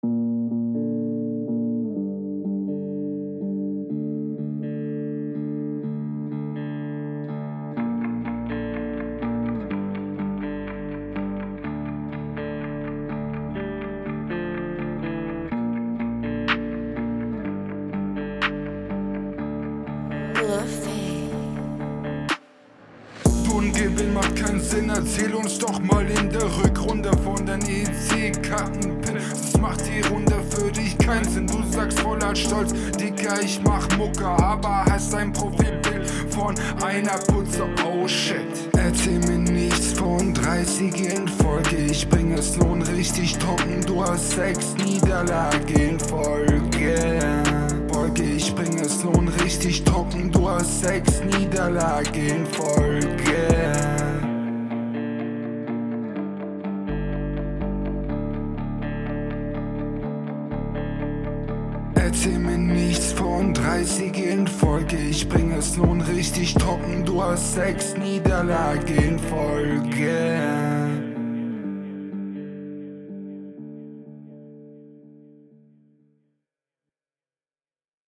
Die Stimme hat Potenzial. Leider versteht man dich oft nicht, auch in deiner RR.